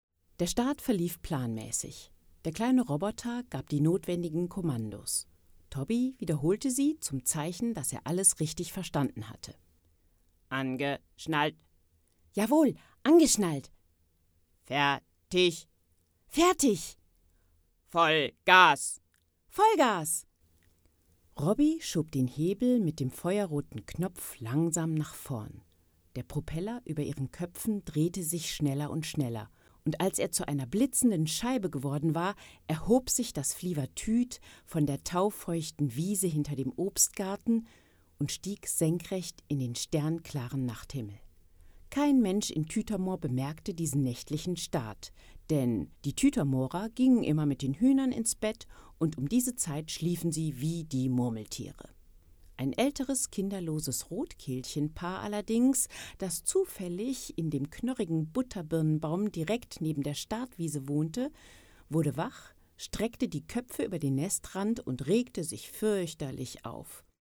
Kinderbuch (Demo)
warm, markant, tief, beruhigend, erzählerisch, vernünftig, psychologisch, seriös